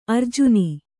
♪ arjuni